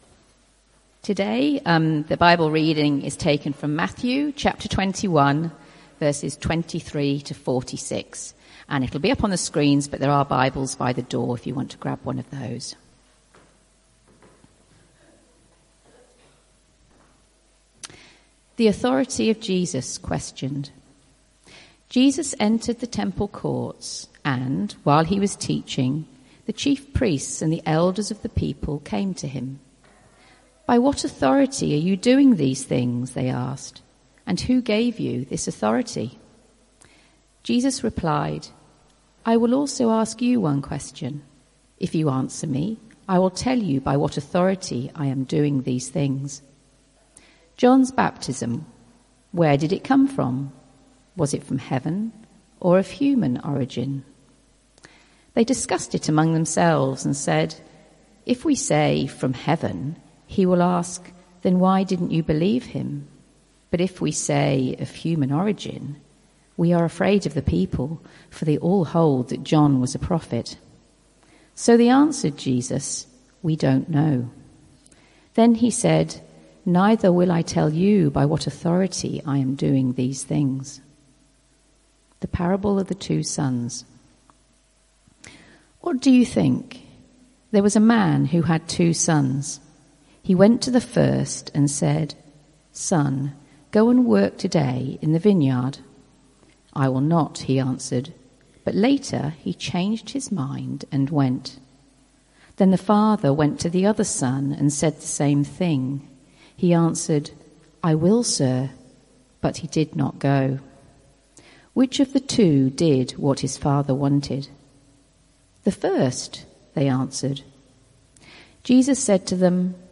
Type: Sermons